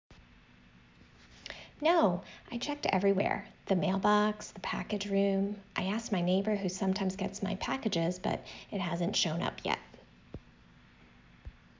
An utterance is a short, uninterrupted stretch of speech that one speaker produces without any silent pauses.